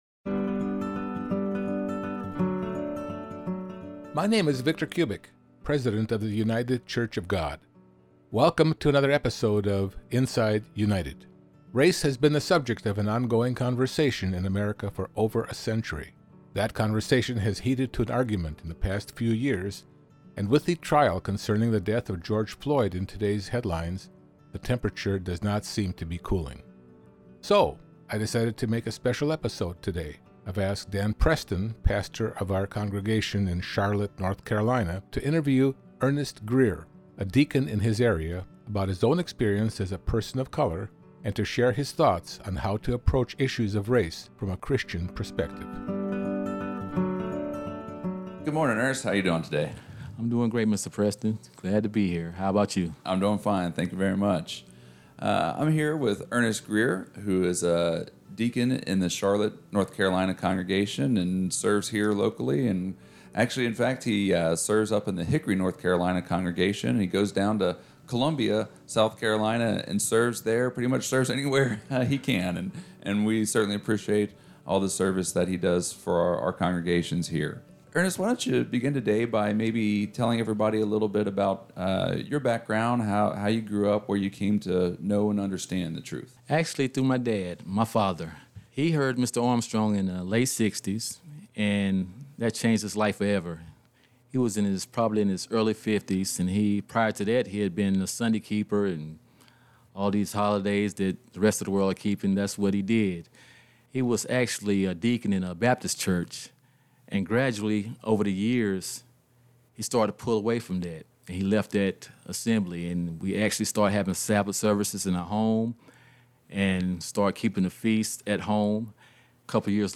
interviewing a Deacon in his area